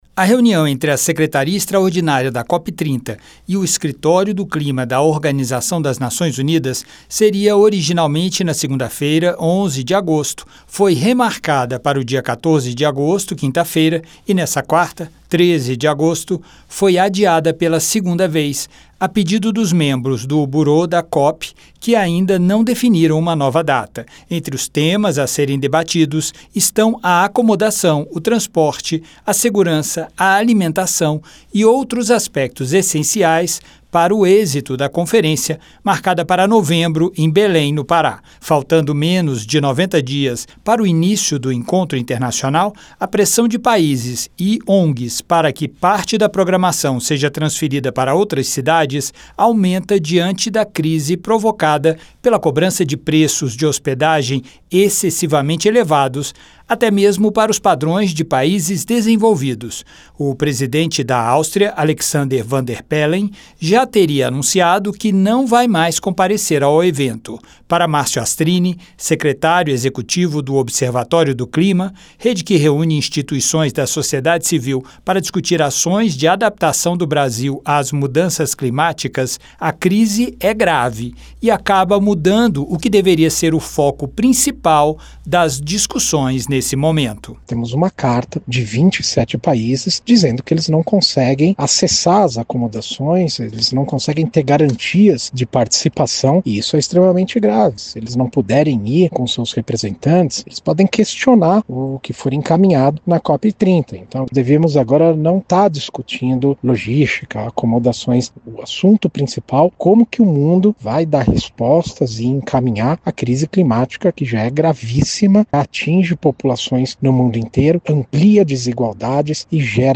Apesar das críticas que delegações estrangeiras vêm fazendo aos custos de hospedagem para a COP 30, que acontecerá em novembro, inclusive com pedidos para que a sede do evento seja alterada para outra cidade, o senador Fabiano Contarato (PT-ES) defendeu a manutenção de Belém como sede. Ele se manifestou sobre o assunto durante reunião da Comissão de Meio Ambiente (CMA) na terça-feira (12).